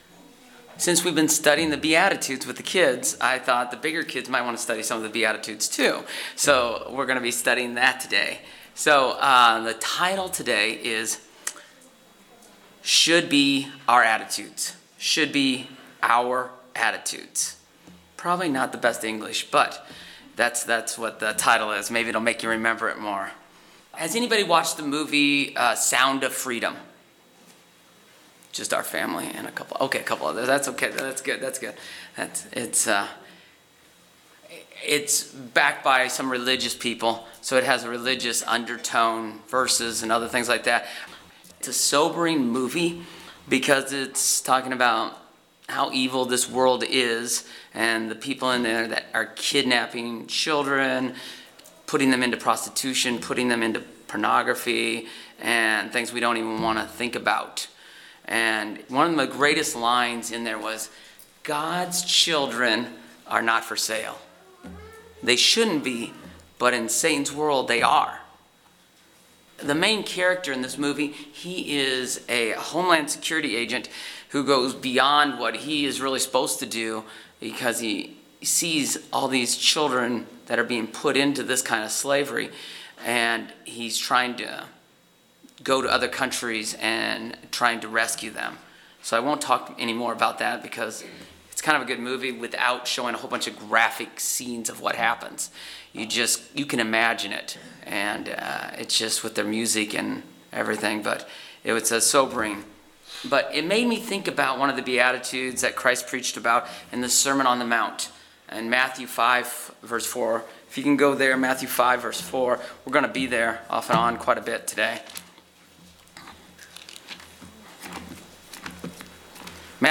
This sermon will take a closer look at the first four "Beattitudes".